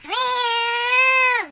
yoshi_flutter.ogg